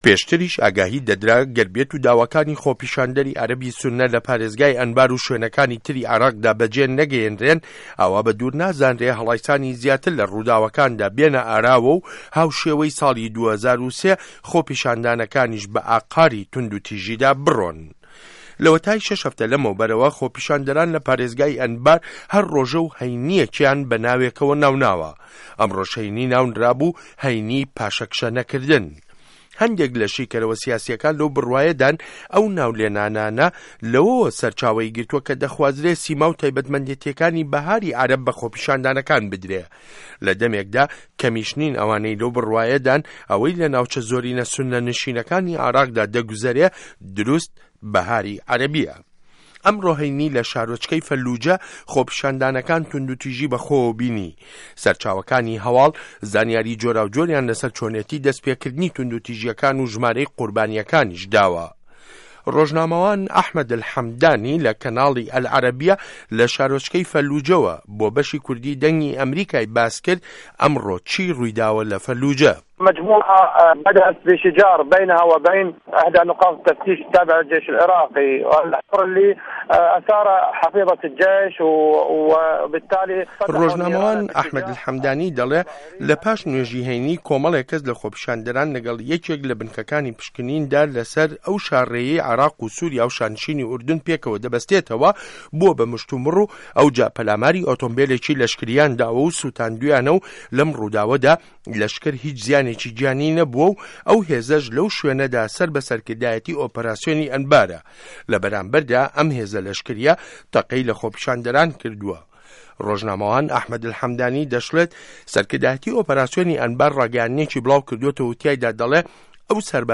ڕاپۆرت له‌سه‌ر ڕووداوه‌کانی فه‌للوجه‌